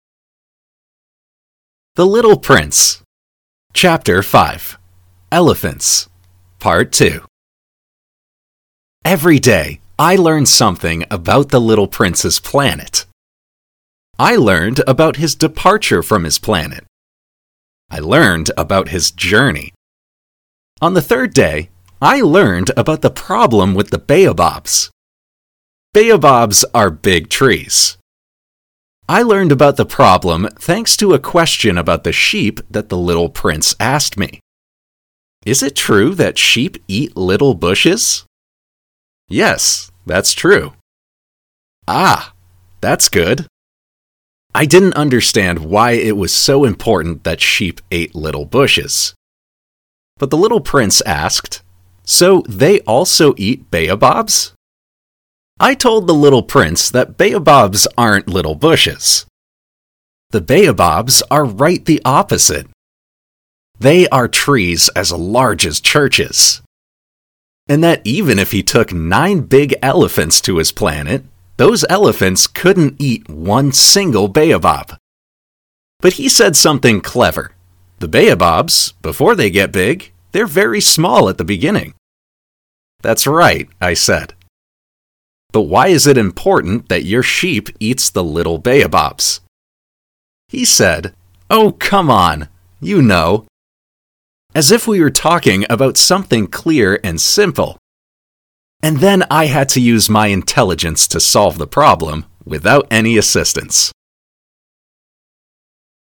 Shadowing
native speakers